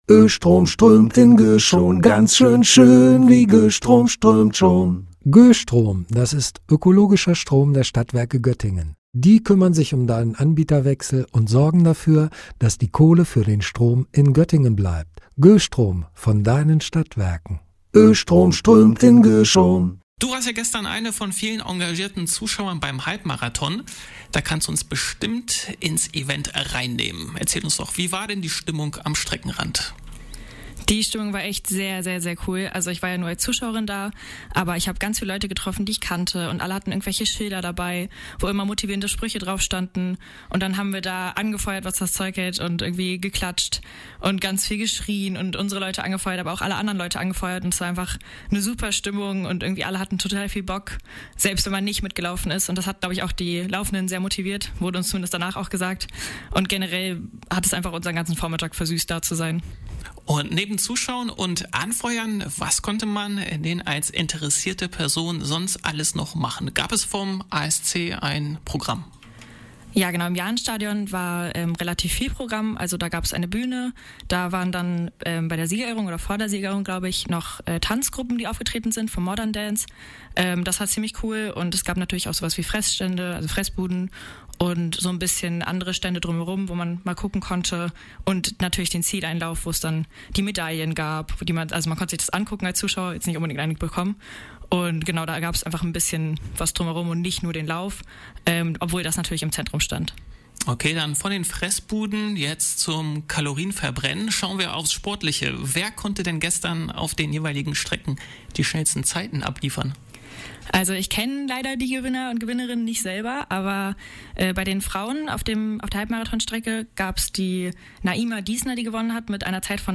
Halbmarathon des ASC im Jahnstadion - Wir waren vor Ort!